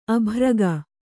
♪ abhraga